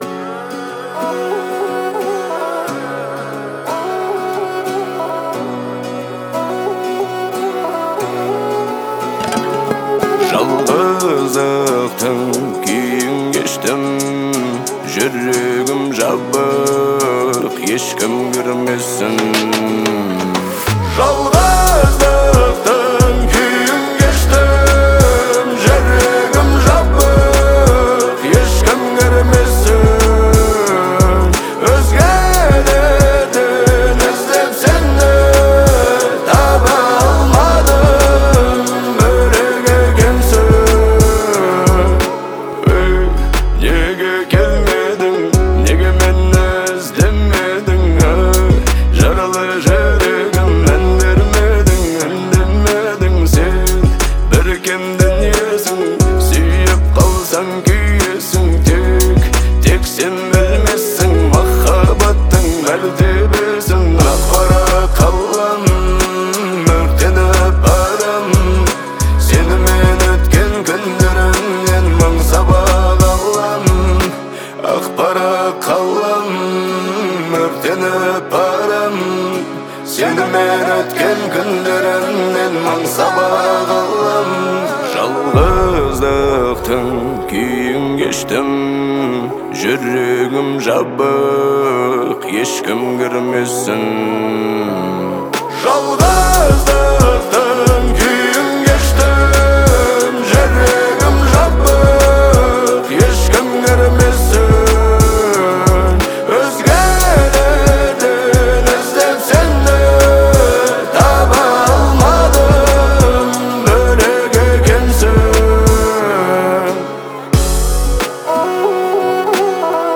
Казахская